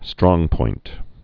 (strôngpoint)